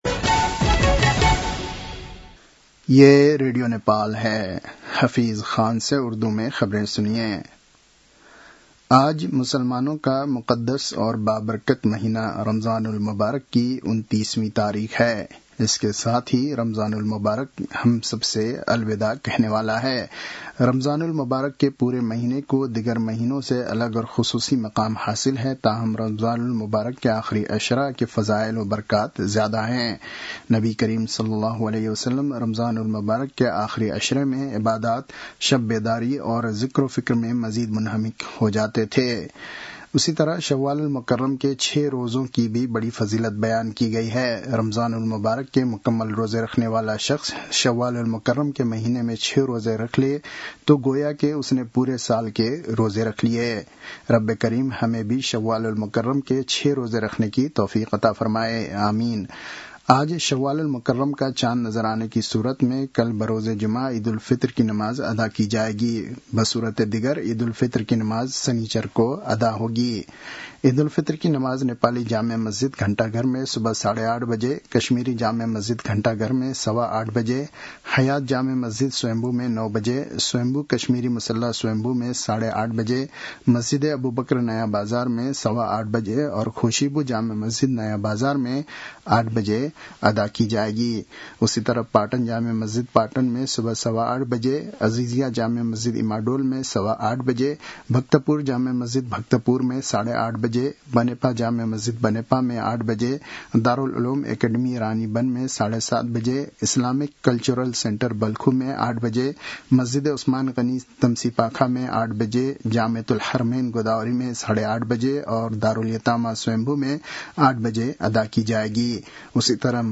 उर्दु भाषामा समाचार : ५ चैत , २०८२
Urdu-news-12-05.mp3